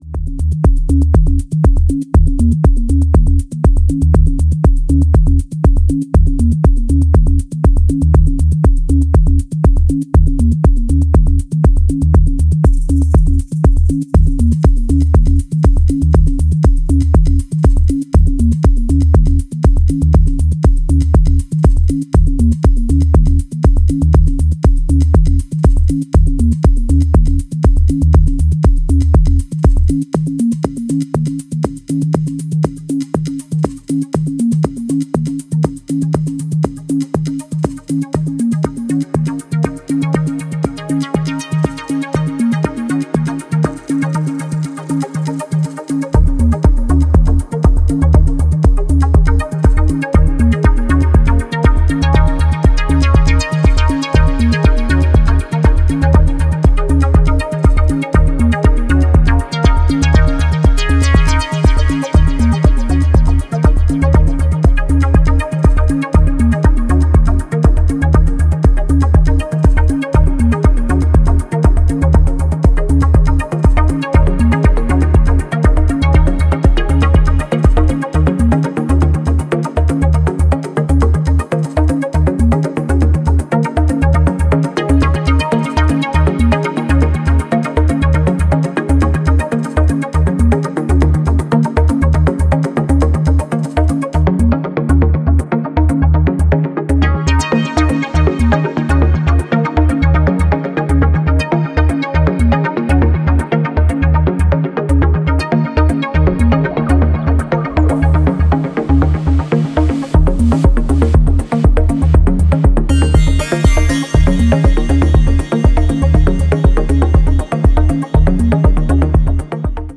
A rocking track with live percusion